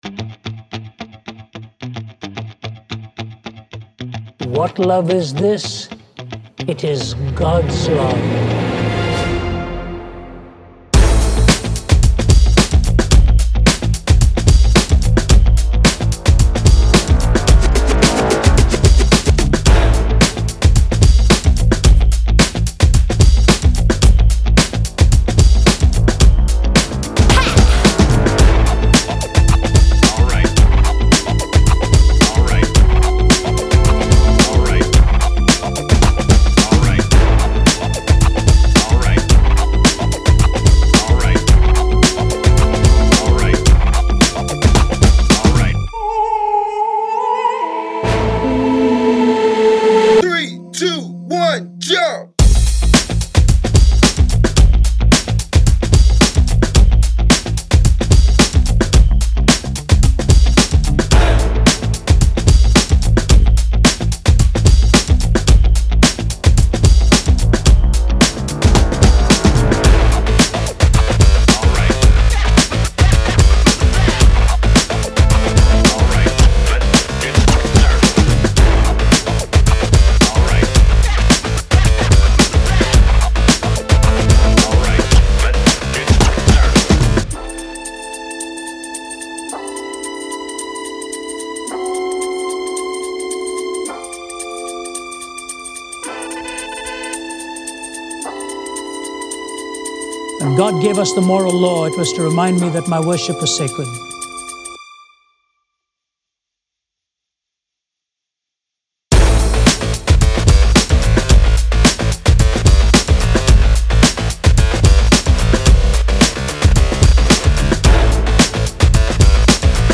брейкбит